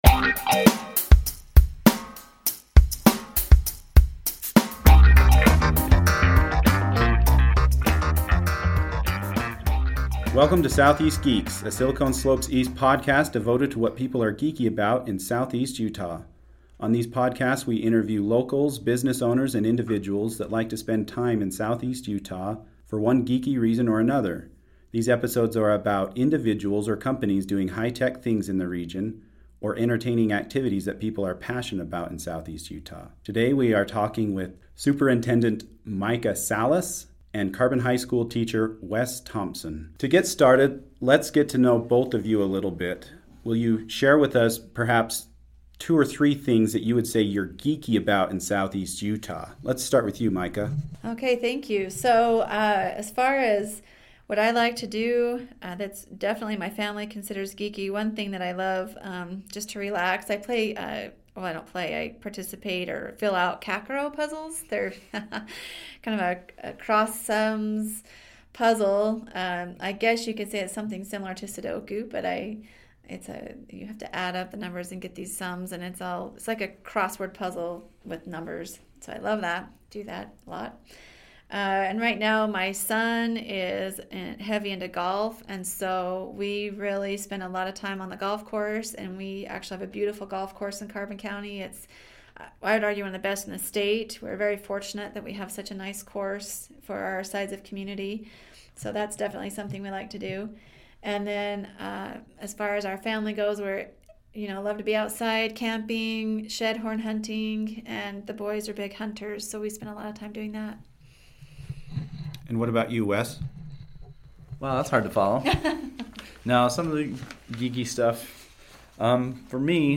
A Silicon Slopes East podcast devoted to what people are geeky about in southeast Utah. On these podcasts we interview locals, business owners and individuals that like to spend time in southeast Utah for one geeky reason or another.